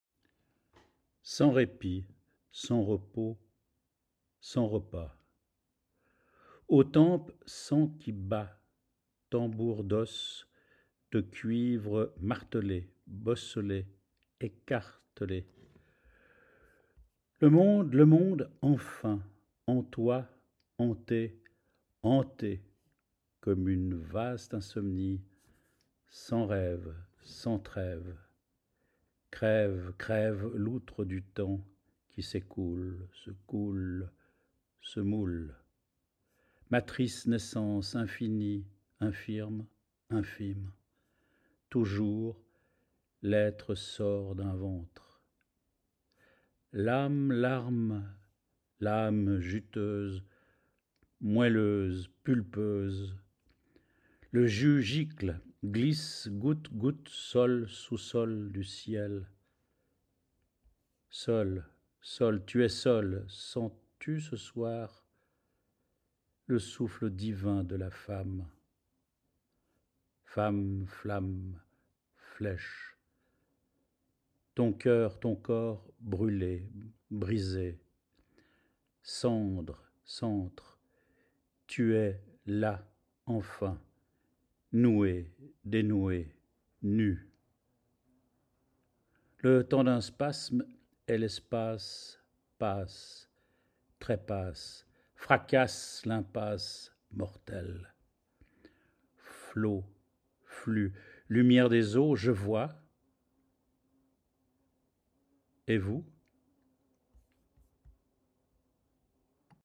Tout commence par des convulsions. Poème à lire et à ouïr.